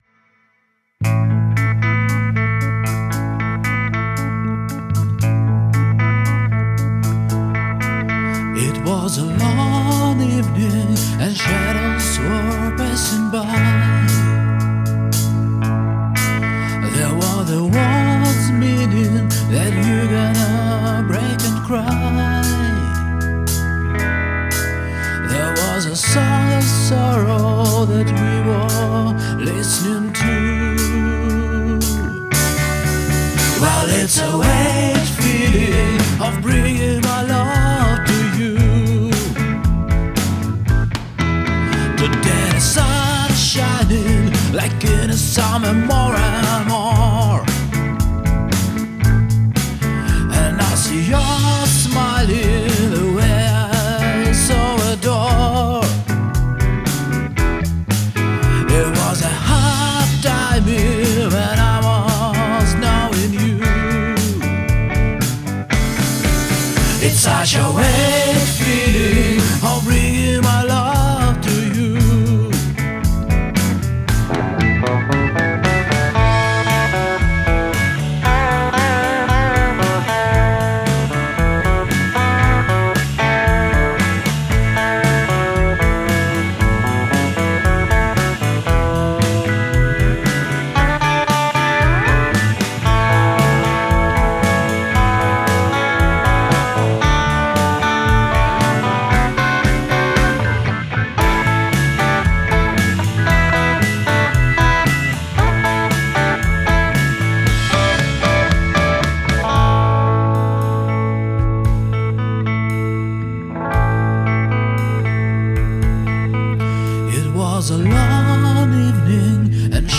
Indie Rock. Weird feeling of
Простой англоязычный инди-рок в два притопа.